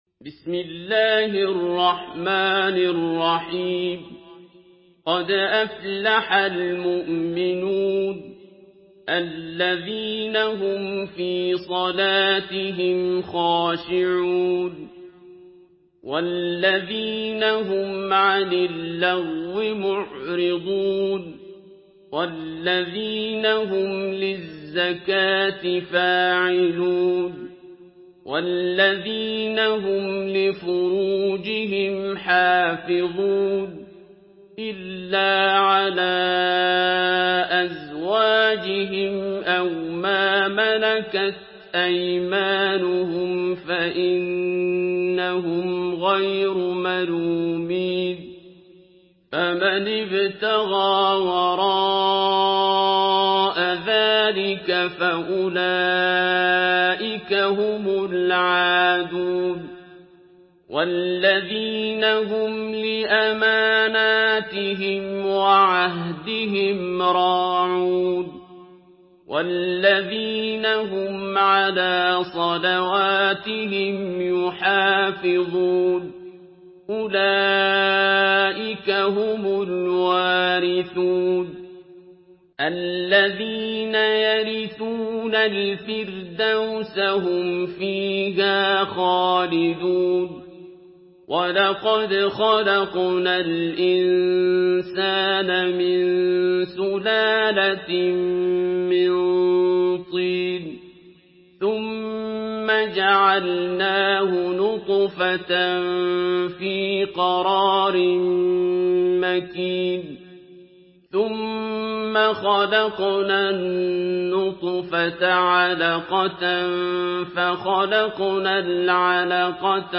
Surah Al-Muminun MP3 by Abdul Basit Abd Alsamad in Hafs An Asim narration.
Murattal Hafs An Asim